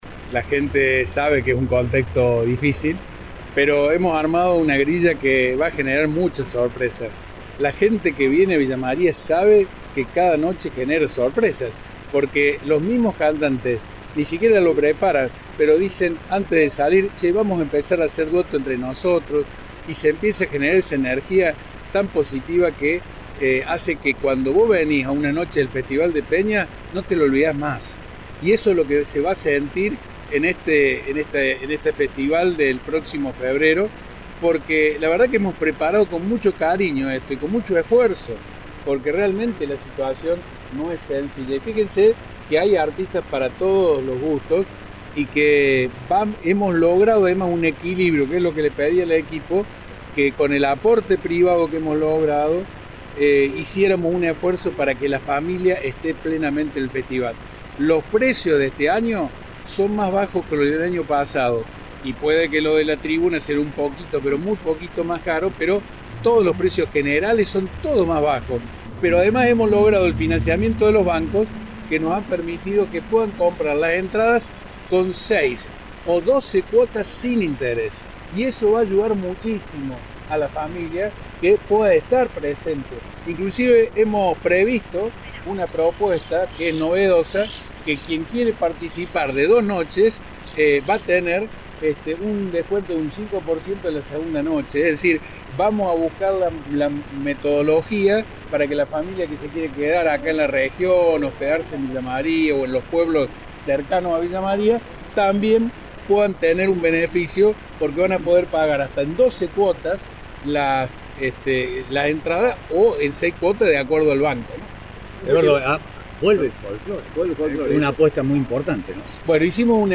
Este martes se anunció en el Sport Social Club, la grilla que trae más de 20 artistas consagrados.
El intendente, Eduardo Accastello, habló del sacrificio por el contexto difícil.